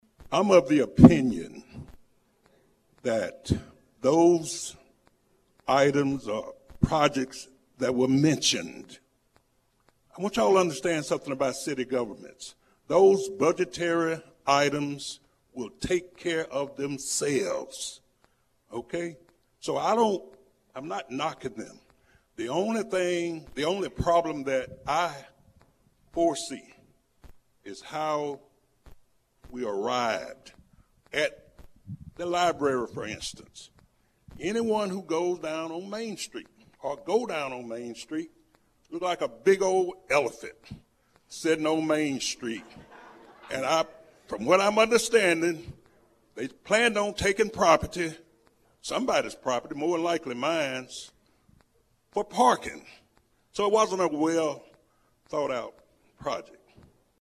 Deltaplex Radio aired a live political debate Tuesday at Pine Bluff High School’s Little Theater with numerous local political candidates in attendance.